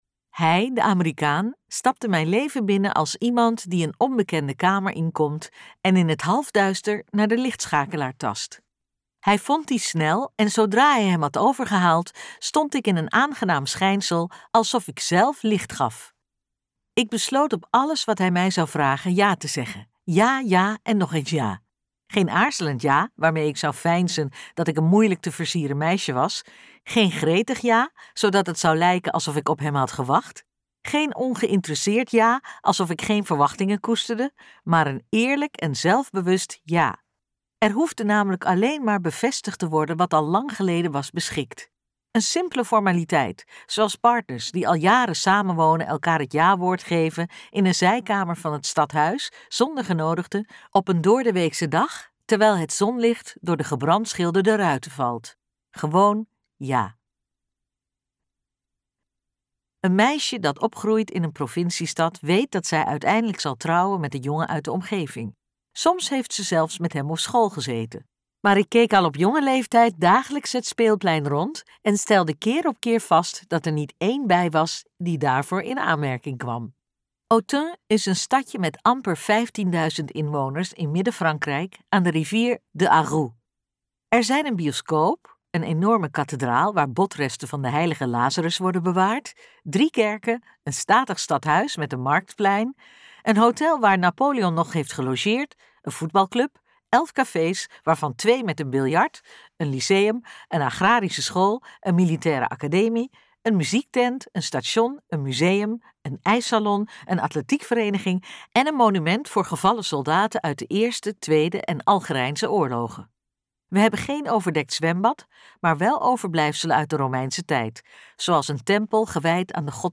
Ambo|Anthos uitgevers - Ik was dat meisje luisterboek